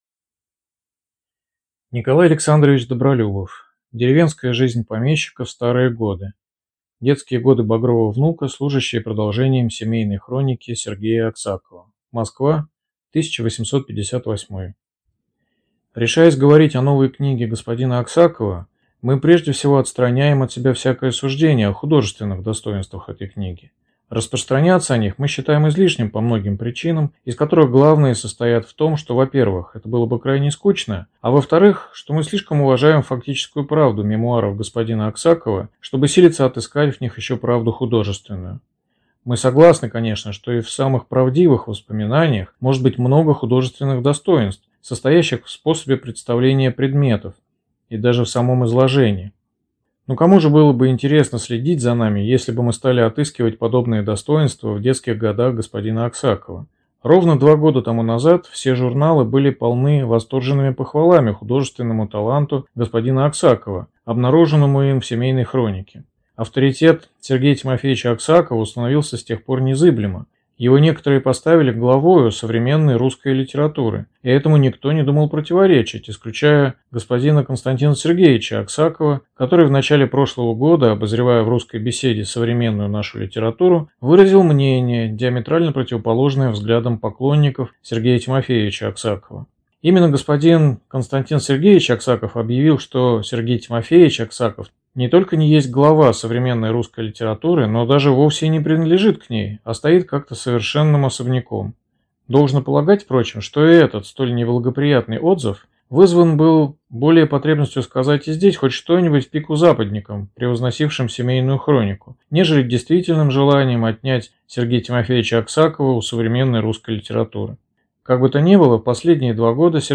Аудиокнига Деревенская жизнь помещика в старые годы | Библиотека аудиокниг